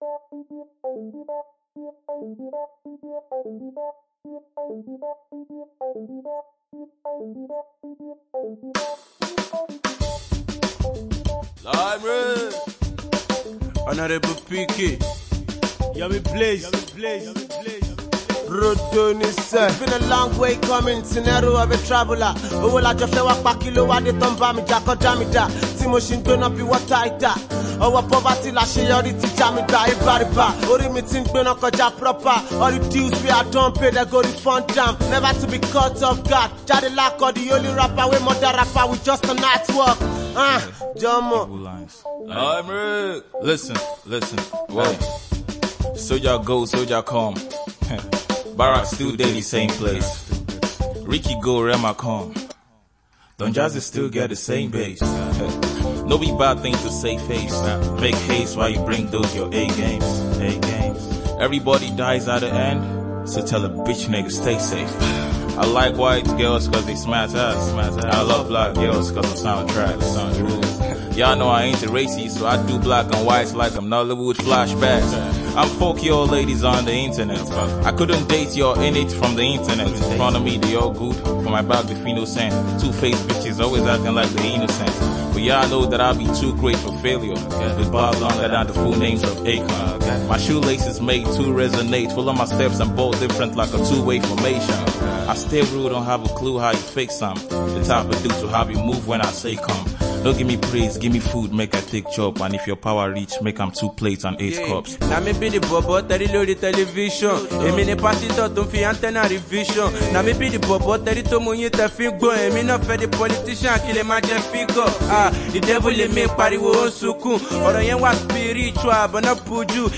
melodious rap jam